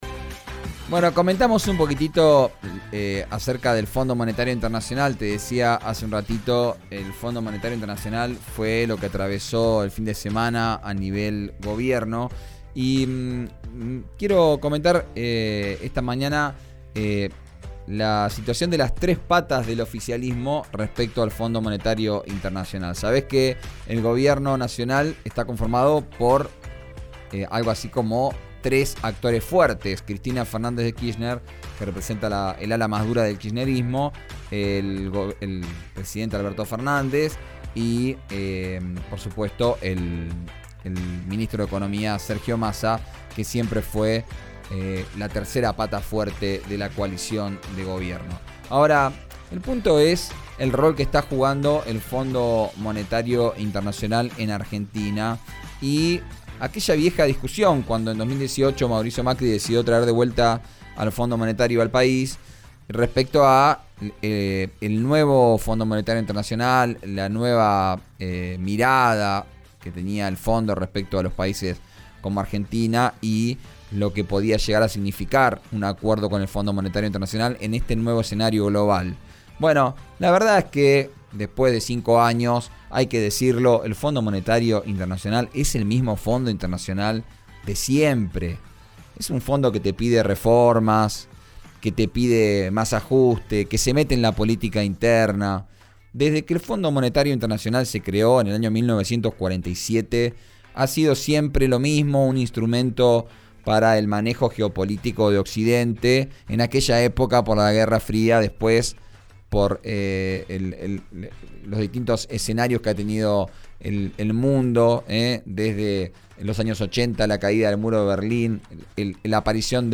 El plenario de la militancia organizado por el kirchnerismo porteño comenzó pasadas las 11.30 de hoy en el microestadio de Ferro con un mensaje de apoyo a la vicepresidenta Cristina Kirchner y un pedido para que sea candidata en los comicios de este año, en el marco de un encuentro que fue clausurado por el diputado nacional Máximo Kirchner.